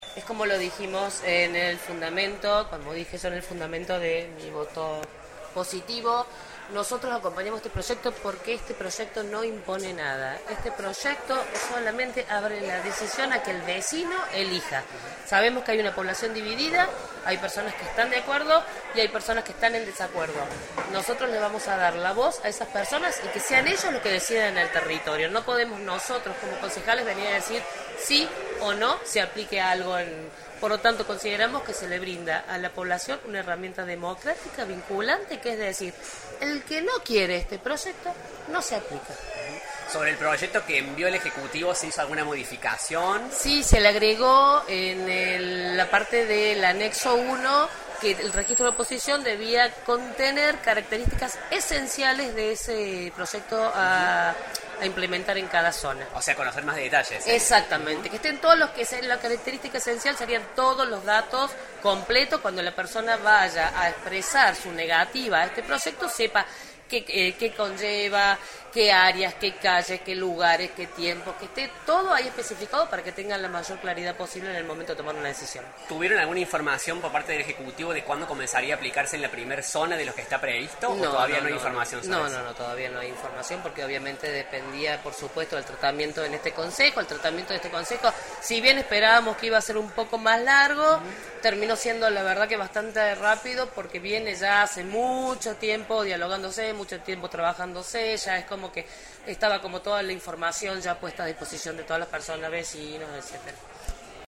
ENTREVISTA A MARIA SOLEDAD VAZQUEZ, CONCEJALA DEL PRO